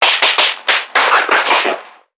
NPC_Creatures_Vocalisations_Infected [43].wav